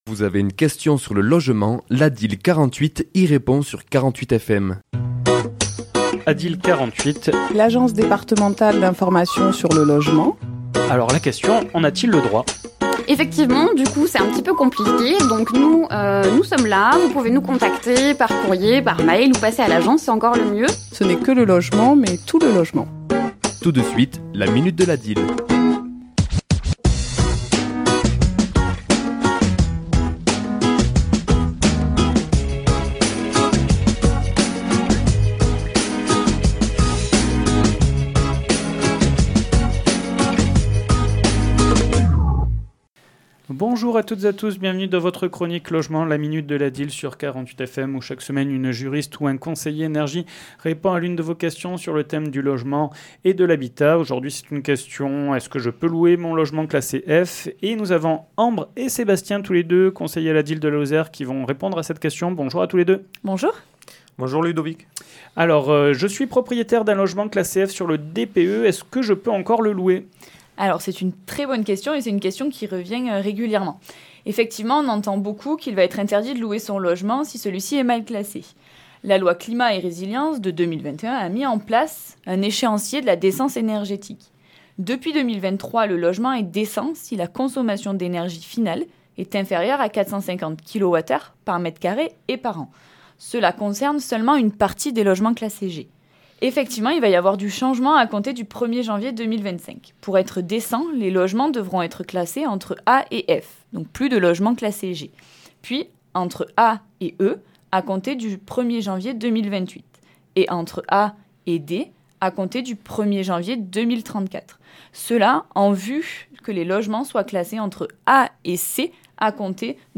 Chronique diffusée le mardi 21 janvier à 11h et 17h10